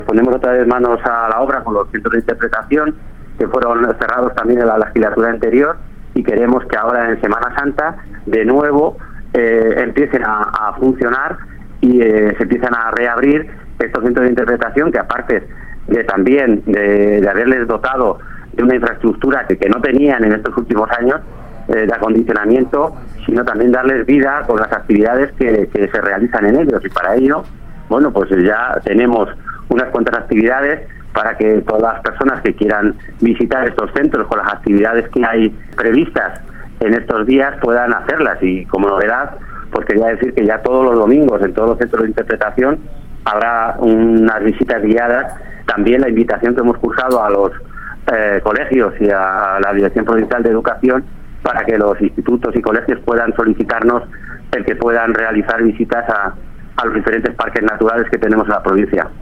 El director provincial de Agricultura, Medio Ambiente y Desarrollo Rural en Guadalajara, Santos López, habla de la apertura de los centros de interpretación de la provincia y las actividades programadas en ellos.